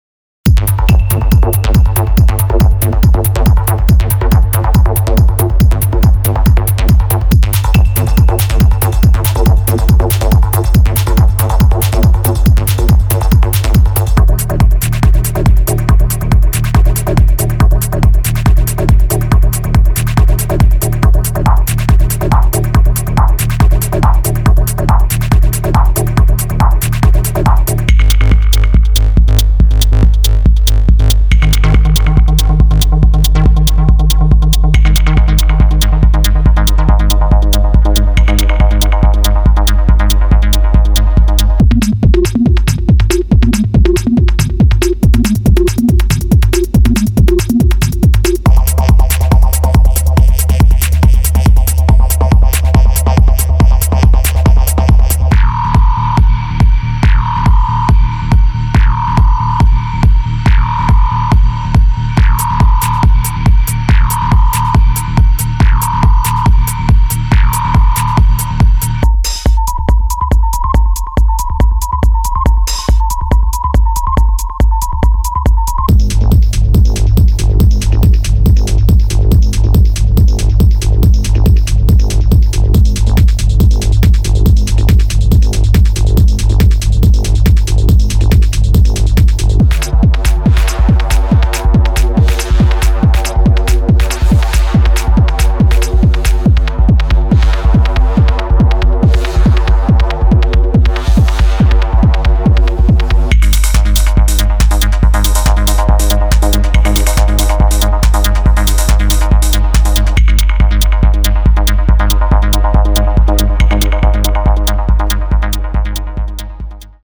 Genre:Techno
このパックには、現代のディープ・ヒプノティック・テクノトラックに必要なすべてが含まれています。
ドラムループ、シンセループ、ベースループなどに加え、自分だけのリズムを作るための多数のワンショットも収録されています。
デモサウンドはコチラ↓